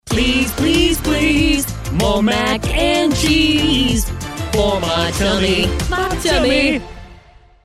catchy jingle for my favorite brand
mac-jingle.mp3